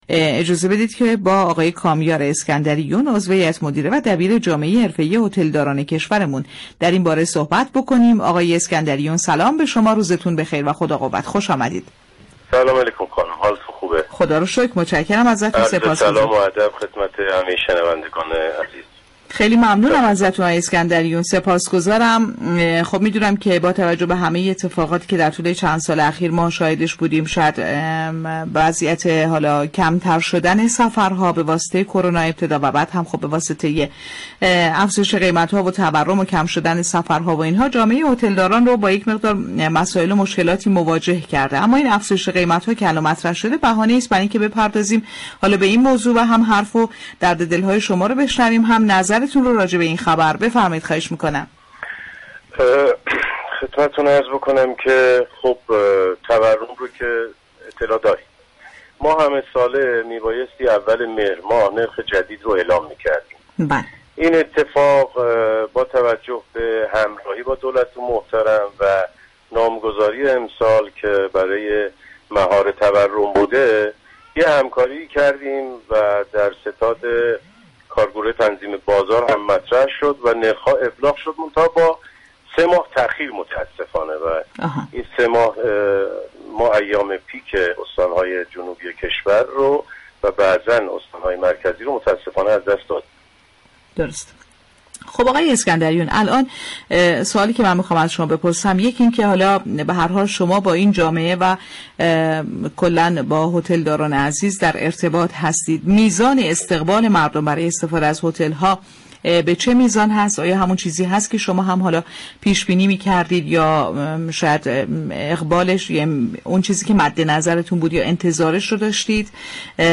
گفت و گو با «بازار تهران»